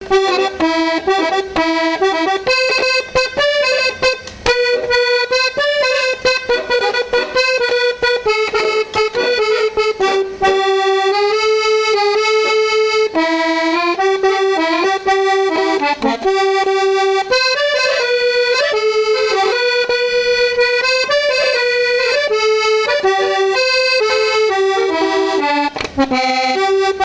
danse : polka
Pièce musicale inédite